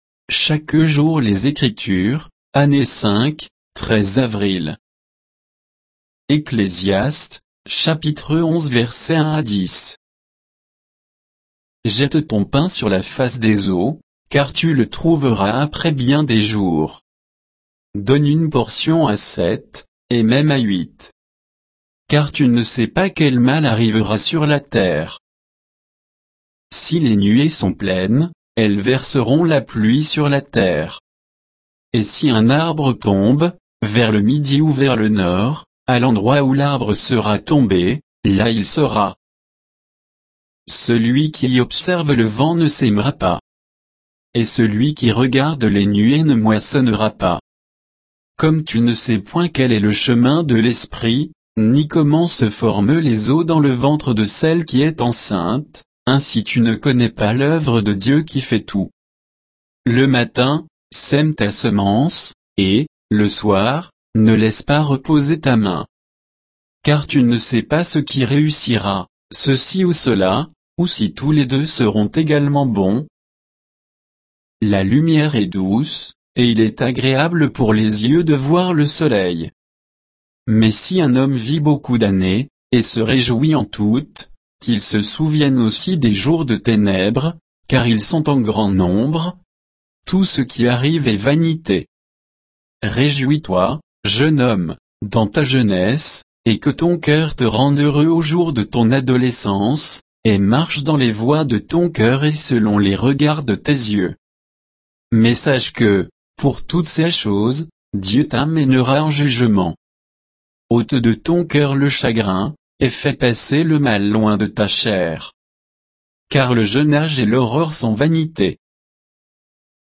Méditation quoditienne de Chaque jour les Écritures sur Ecclésiaste 11, 1 à 10